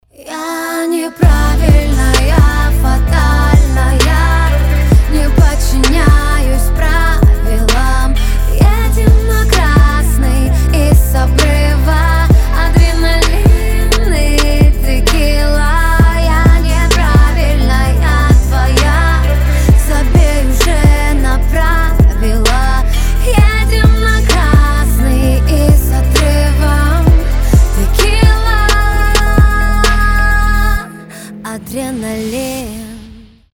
поп
чувственные